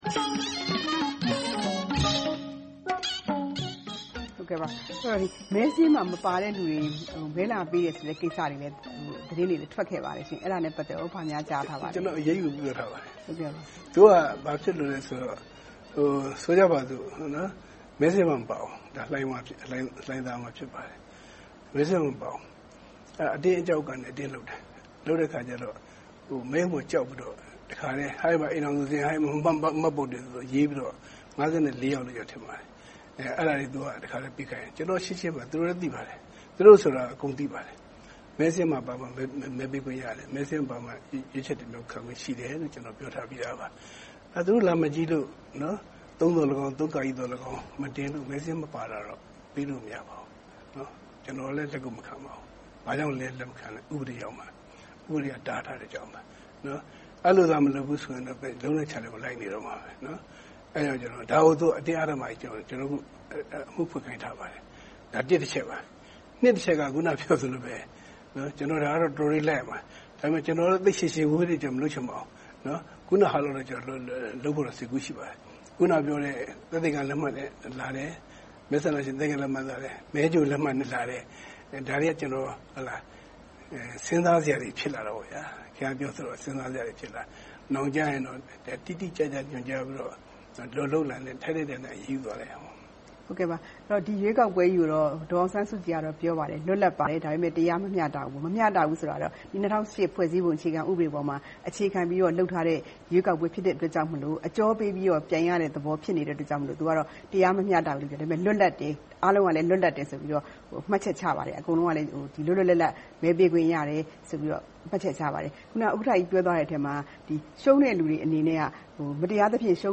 ကော်မရှင်ဥက္ကဋ္ဌ နဲ့ တွေ့ဆုံမေးမြန်းခန်း (အပိုင်း-၂)